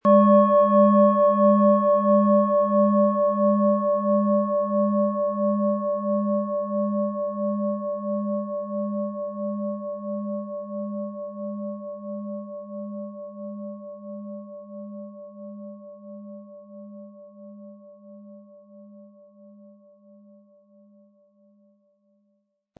PlanetentonMars & Tageston (Höchster Ton)
SchalenformBihar
MaterialBronze